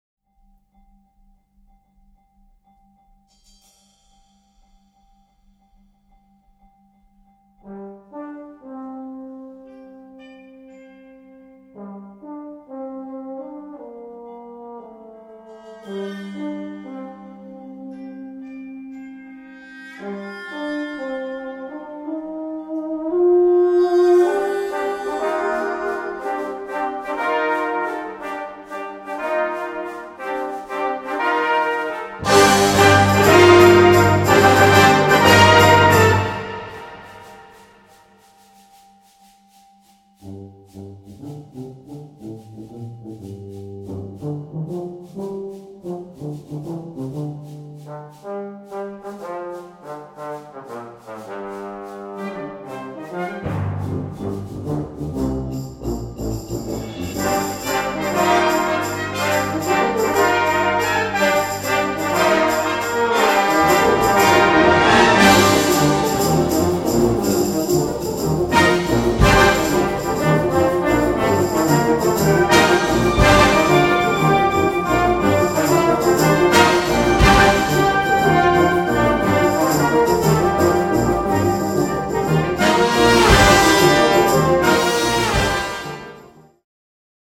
Easy Listening / Unterhaltung / Variété